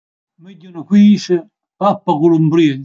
ascolta pronunzia)